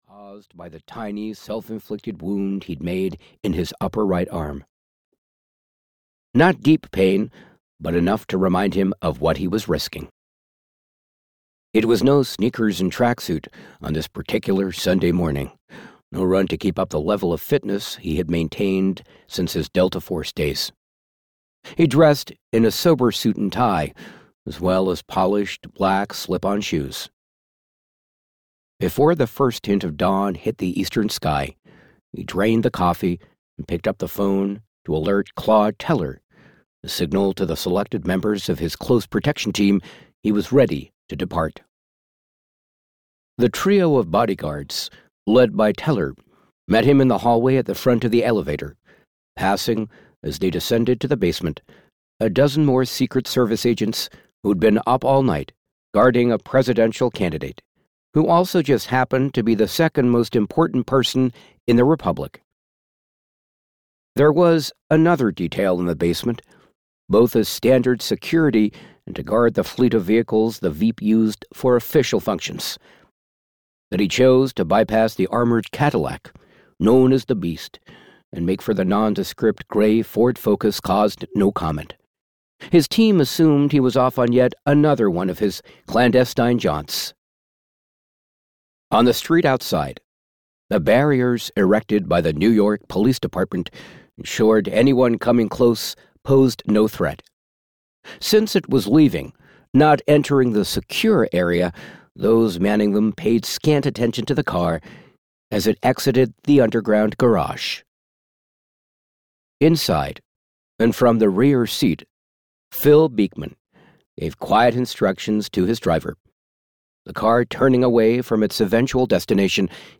Audio knihaTight Lies (EN)
Ukázka z knihy